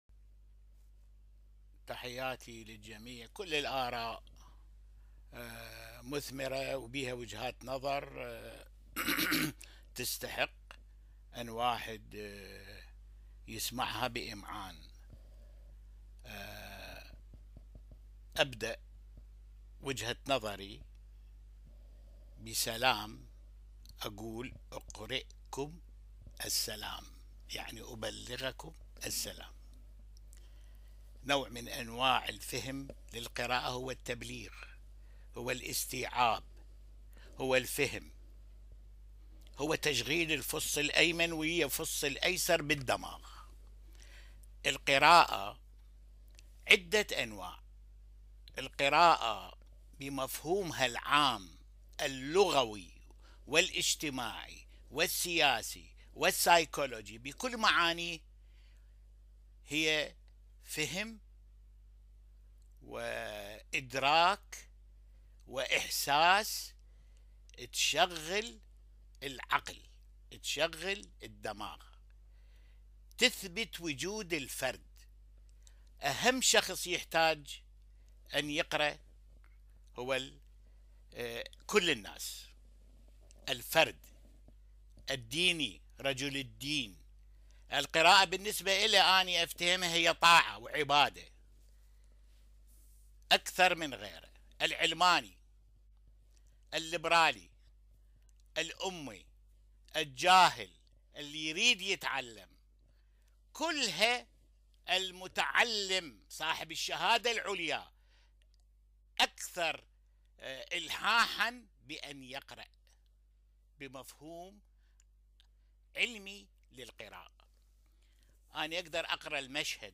كيف نقرأ لكي نستفاد ؟ ندوة